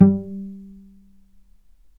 healing-soundscapes/Sound Banks/HSS_OP_Pack/Strings/cello/pizz/vc_pz-G3-mf.AIF at 48f255e0b41e8171d9280be2389d1ef0a439d660
vc_pz-G3-mf.AIF